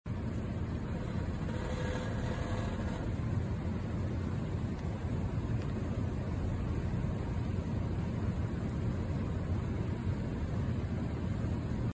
It happens on cold start and remains for few mins then goes away and comes back sometime when ideal.